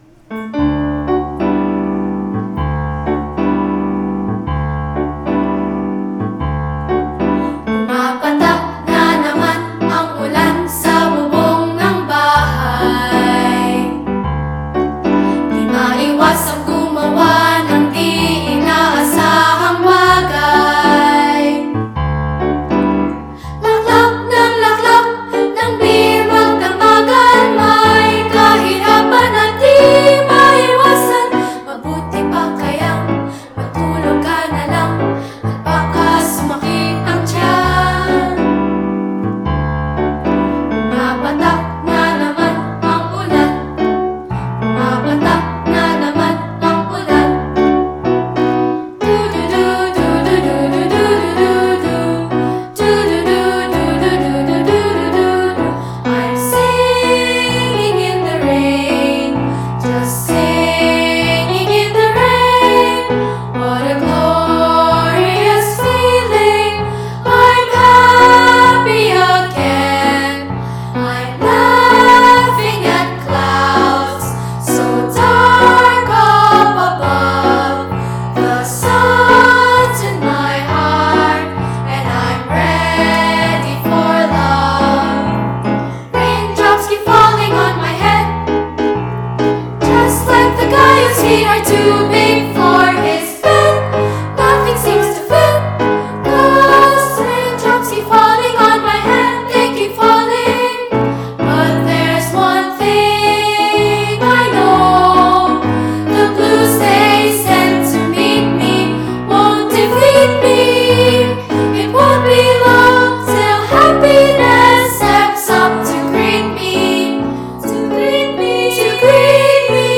The choir is composed of musically-talented children ages 6-18 (6-18 for girls, 7-13 for boys) of all economic, racial and ethnic backgrounds from Metro Manila, coming together as one symphony to create a unique sound.
soa-rain-medley.mp3